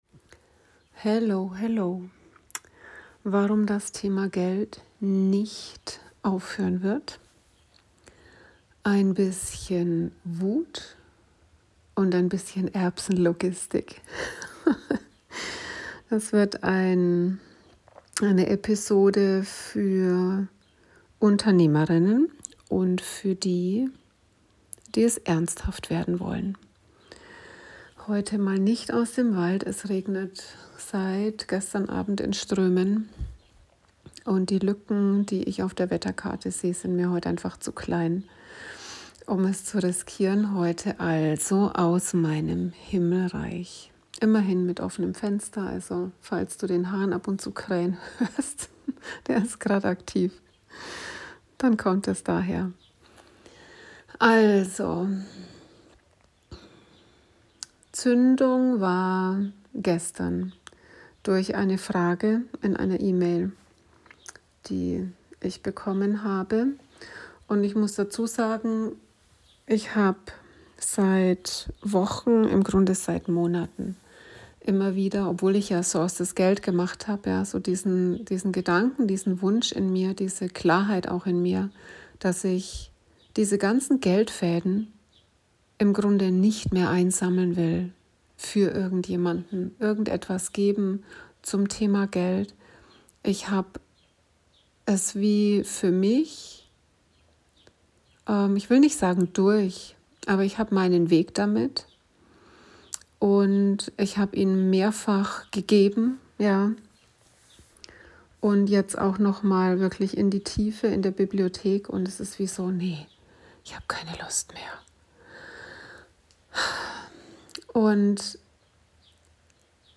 Eine intime Episode, gespickt mit Triggern, begleitet von Regen & Wind ums Dach in meinem HimmelReich.